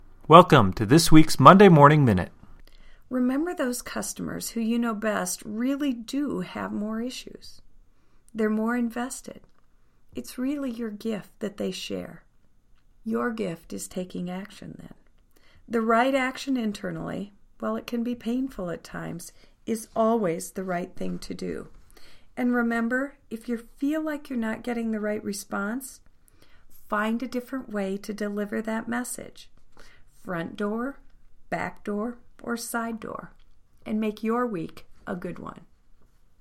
Welcome to B/Mc’s Monday Morning Minute, an audio message to jump start your week.